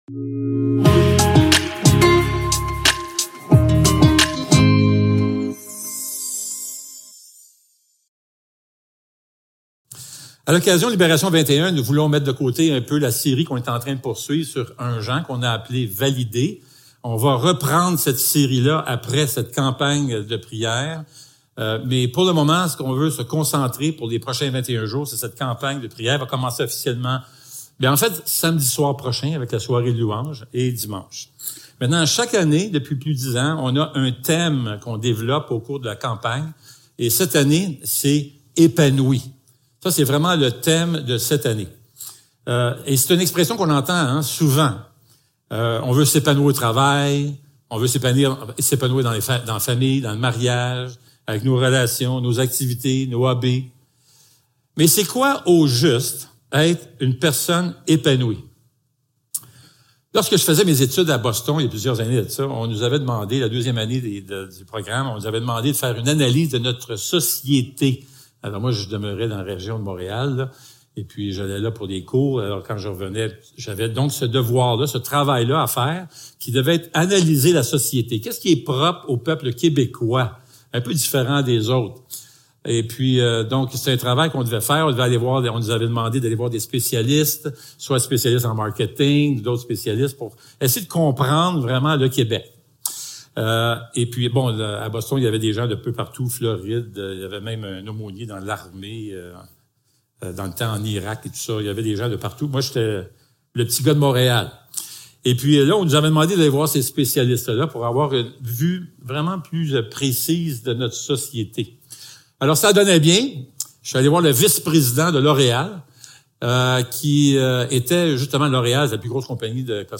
Matthieu 7.7-11 Service Type: Célébration dimanche matin Description